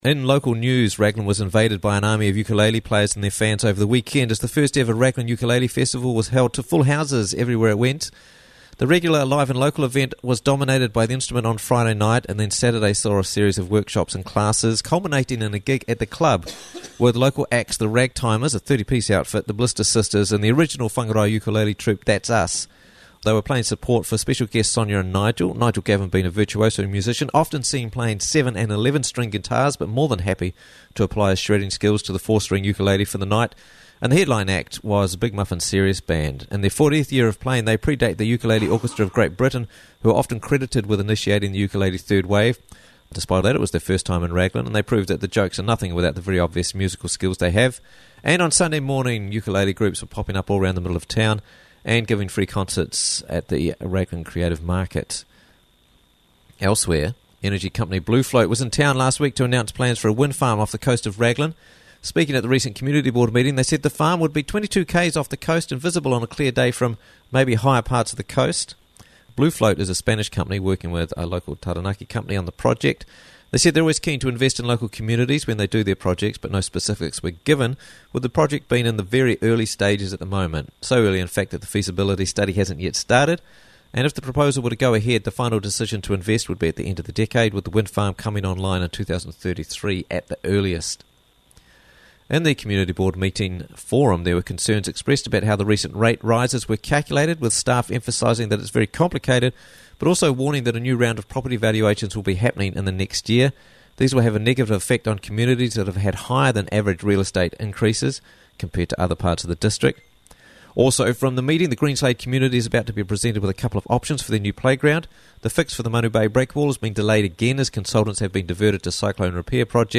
Raglan News Monday 11th September 2023 - Raglan News Bulletin